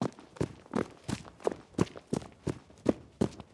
Babushka / audio / sfx / Footsteps / Loop / SFX_Footsteps_Run_01.wav
SFX_Footsteps_Run_01.wav